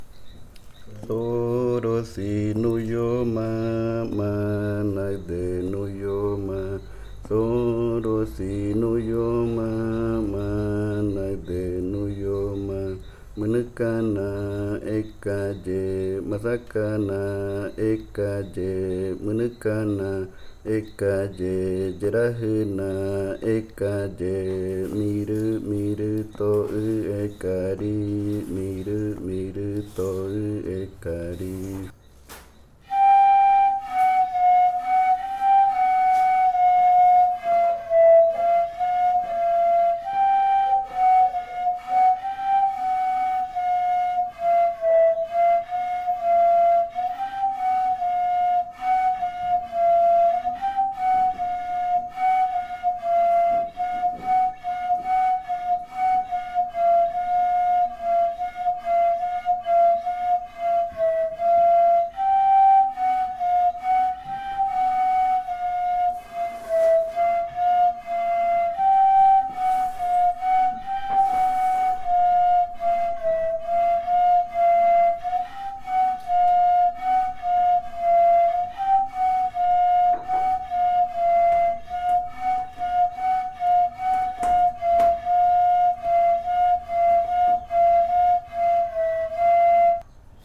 Grupo de danza Kaɨ Komuiya Uai
Canto Zorozi nuioma mɨnɨkana ekaye (lengua murui) e interpretación del canto en pares de reribakui.
Chant Zorozi nuioma mɨnɨkana ekaye (Murui language) and performance of the chant in pairs of reribakui flutes.
smaller, female flute
larger, male flute